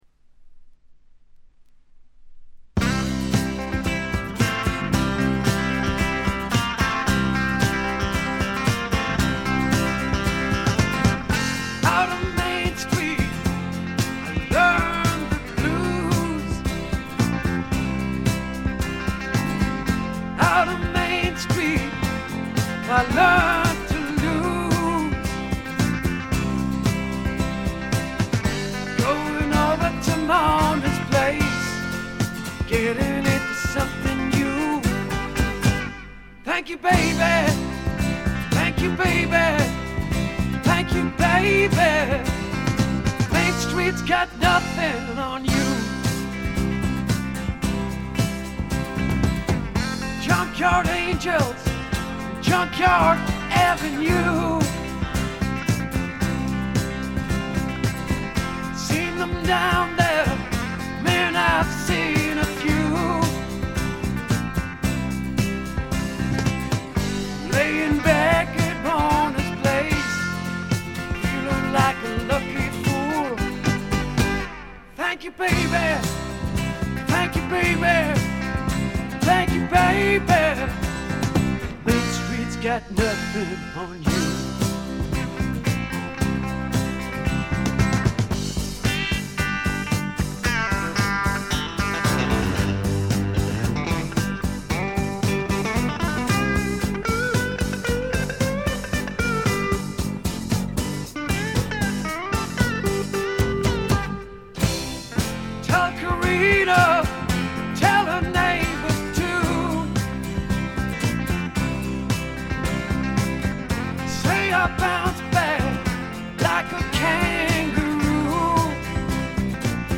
ところどころでチリプチ。
内容は身上である小気味良いロックンロール、軽快なフォークロック、メロディアスなポップ作等バラエティに富んだもの。
試聴曲は現品からの取り込み音源です。